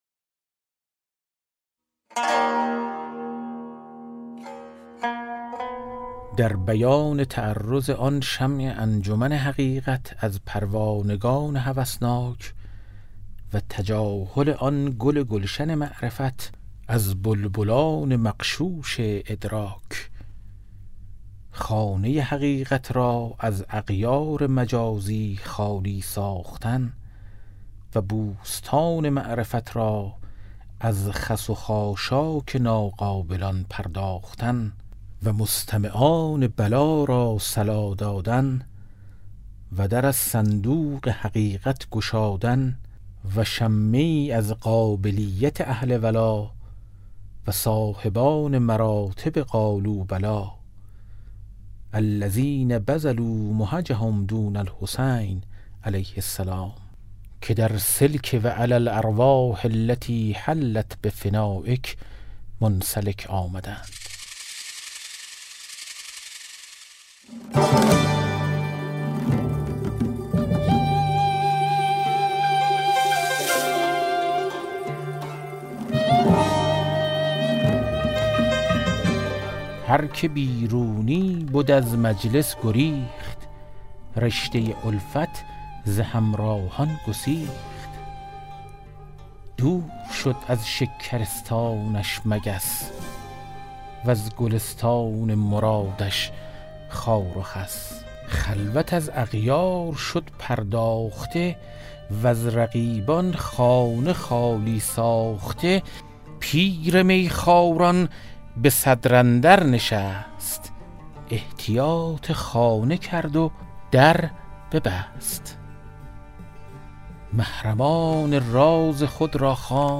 کتاب صوتی گنجینه الاسرار، مثنوی عرفانی و حماسی در روایت حادثه عاشورا است که برای اولین‌بار و به‌صورت کامل در بیش از ۴۰ قطعه در فایلی صوتی در اختیار دوستداران ادبیات عاشورایی قرار گرفته است.